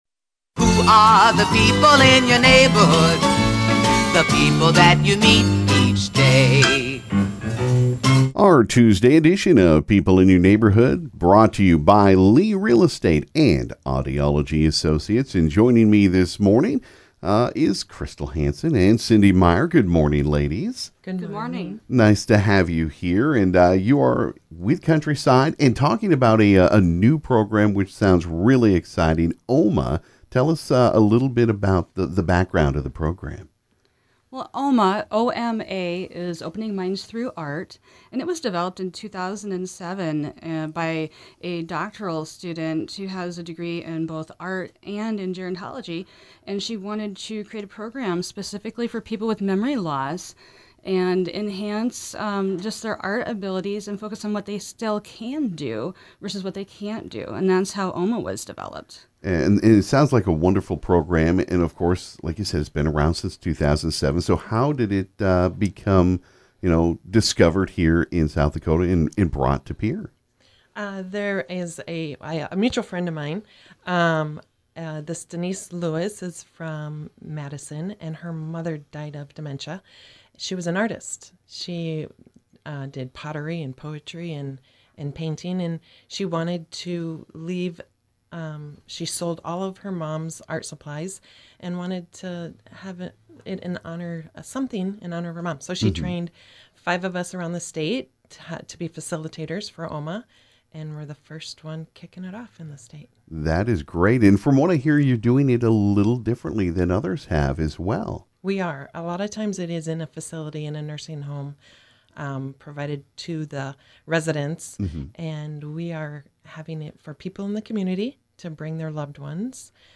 This morning at the KGFX studio